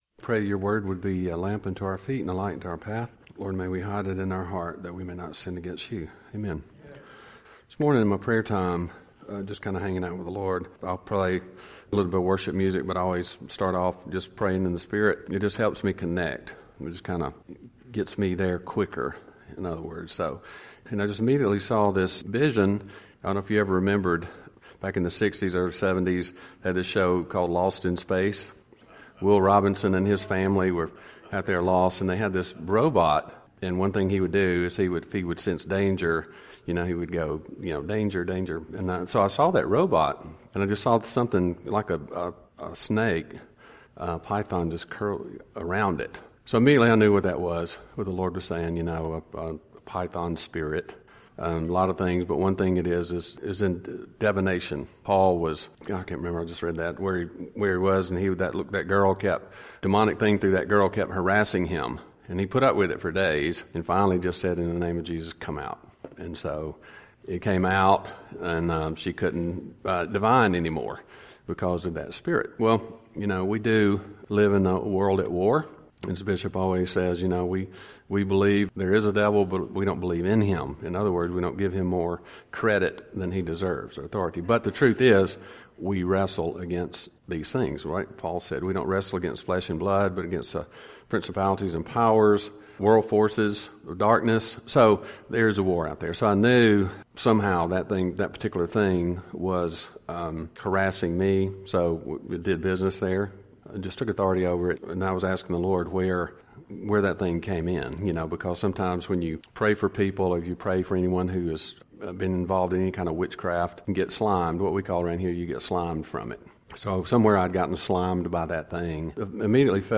Service Type: CTK Noon Service